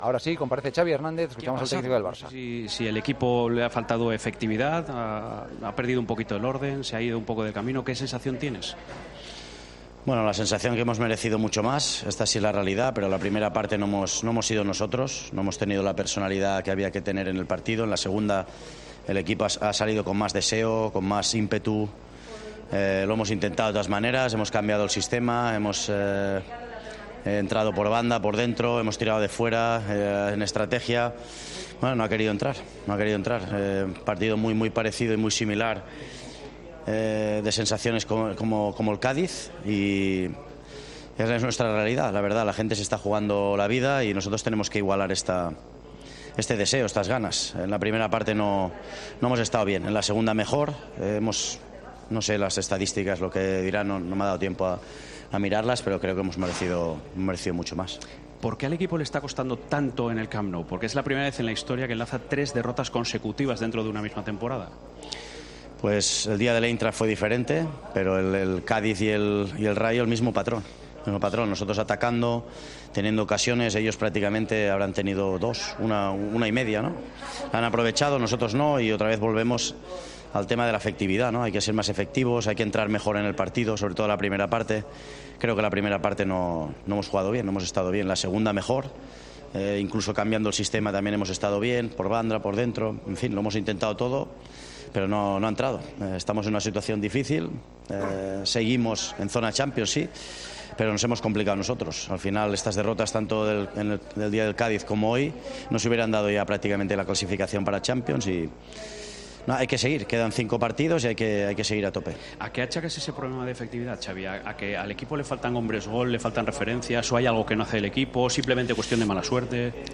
Xavi Hernández analizó con gesto muy serio la derrota frente al Rayo Vallecano en los micrófonos de Movistar, donde cree que hay una clara diferencia entre la actitud del equipo en la primera y en la segunda mitad.